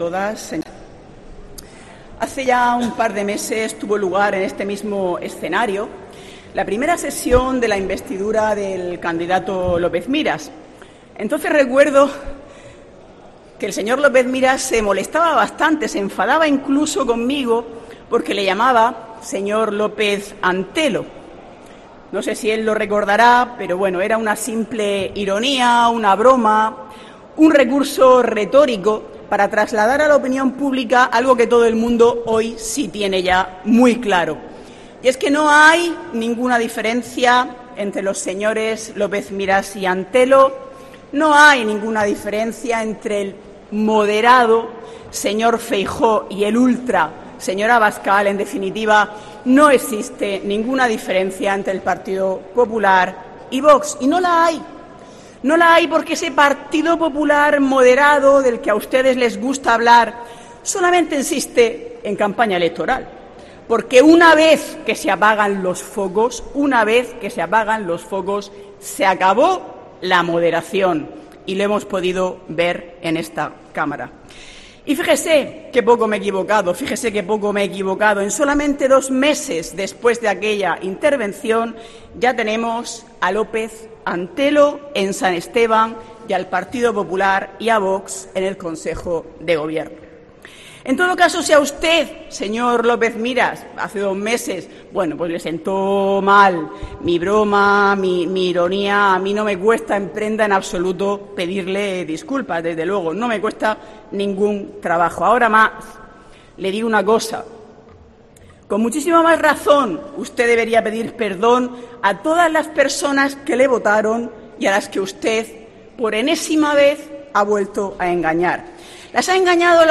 María Marín, portavoz de Podemos en Asamblea Regional
Lo ha dicho durante su intervención en al segunda sesión del debate de investidura, en la que previsiblemente el popular Fernando López Miras será designado presidente, tras sellar un acuerdo con los de Santiago Abascal, que ocuparán las consejerías de Fomento y de Seguridad, Interior y Emergencias, con rango de vicepresidencia.